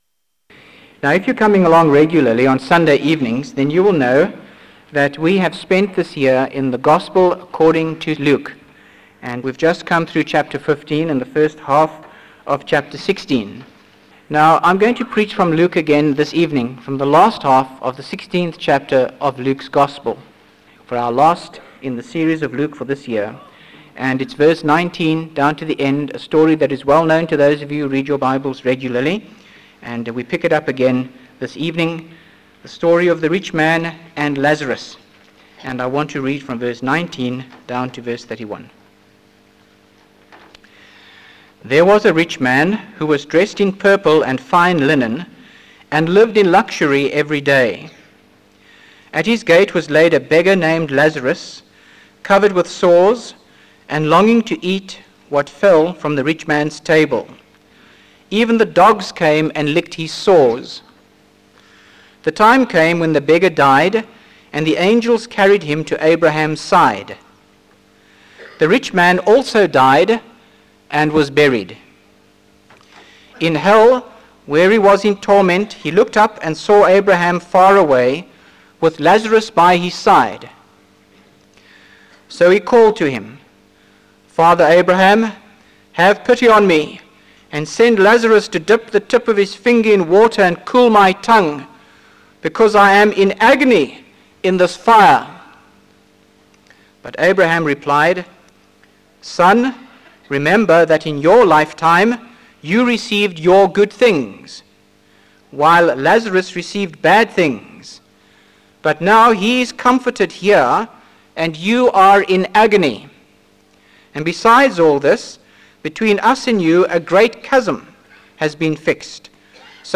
by Frank Retief | Jan 27, 2025 | Frank's Sermons (St James) | 0 comments